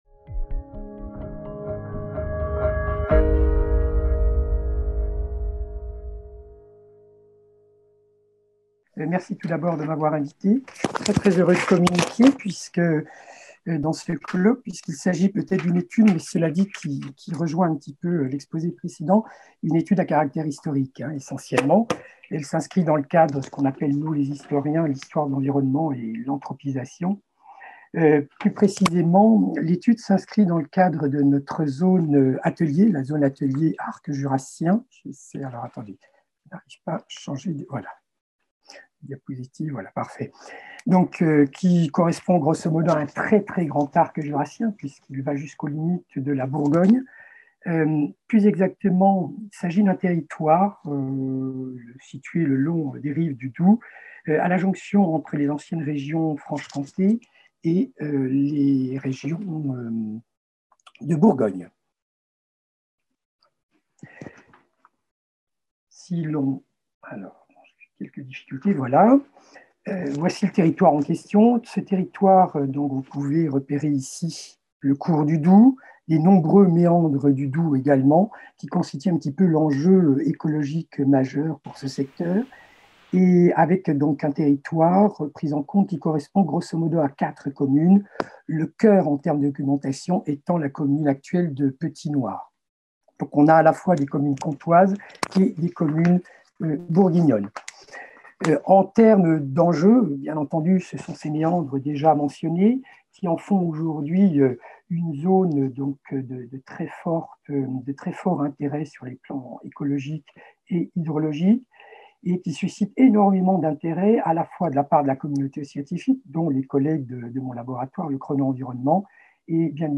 5e colloque des Zones Ateliers – CNRS - 2000-2020, 20 ans de recherche du Réseau des Zones Ateliers.